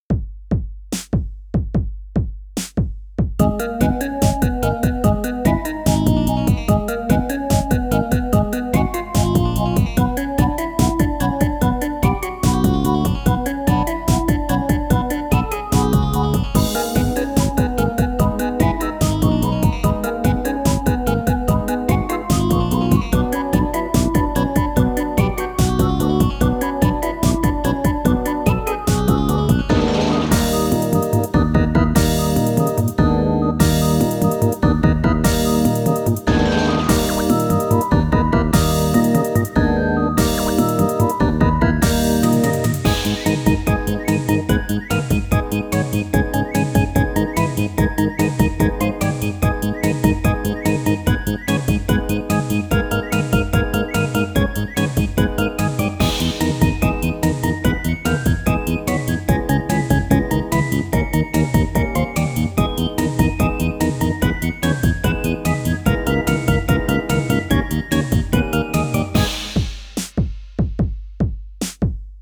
ogg(L) テクノ コミカル 不穏
コミカルでどこか不安なテクノ。